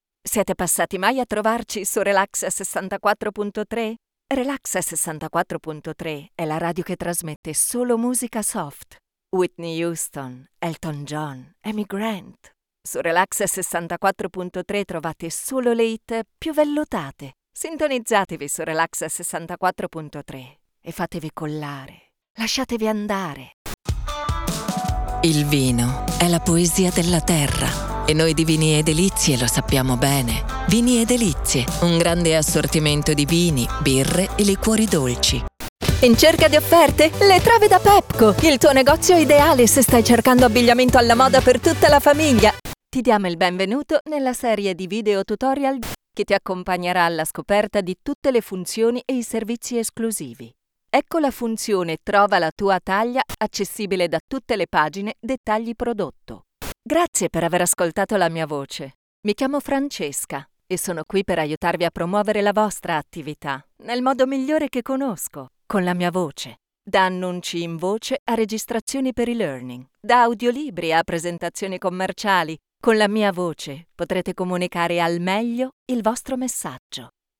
Commercial Demo
I built a home studio with audio silent cabin where I log every day.
DeepMezzo-Soprano